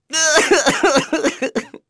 Neraxis-Vox_Sad.wav